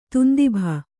♪ tundibha